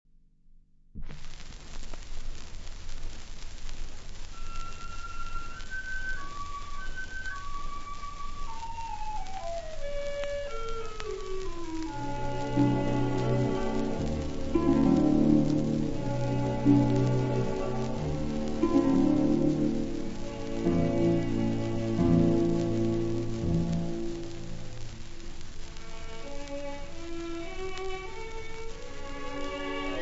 • Croiza, C. [interprete]
• Dufranne, Hector [interprete]
• Registrazione sonora musicale